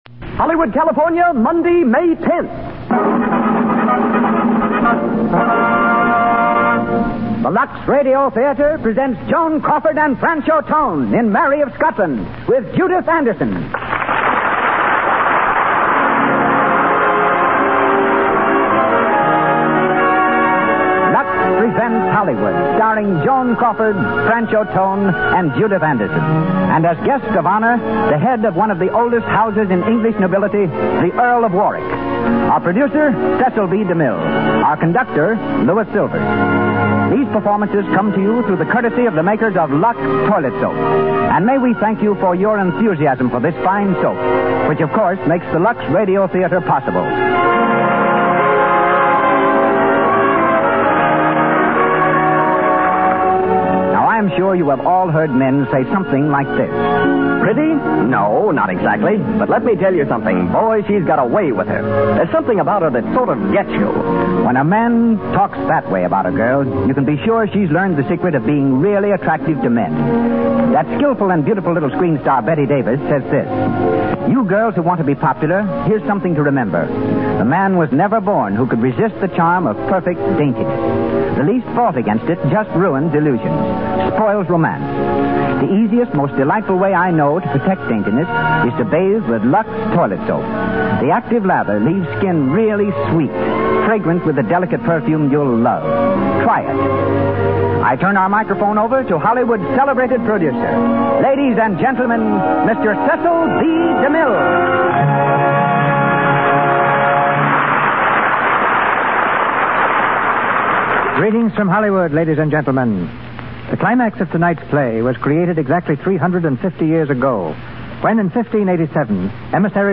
starring Joan Crawford and Franchot Tone